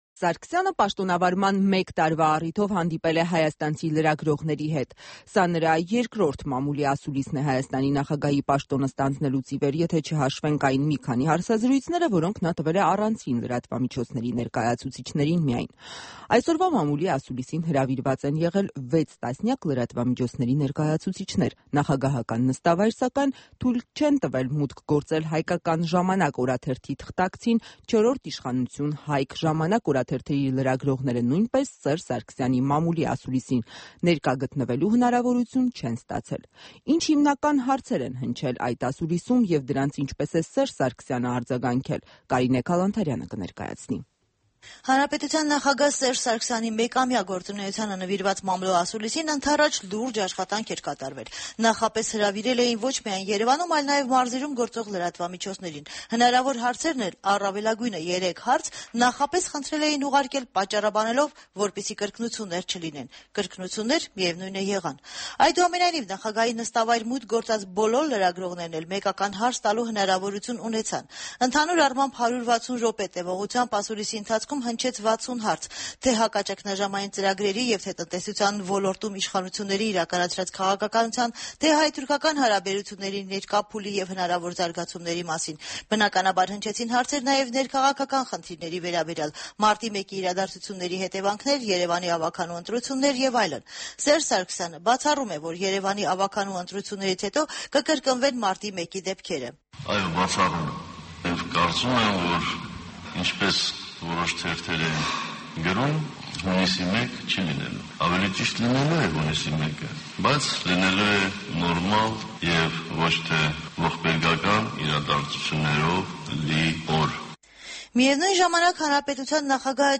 Նախագահի ասուլիսը
Նախագահի ապրիլի 10-ի ասուլիսը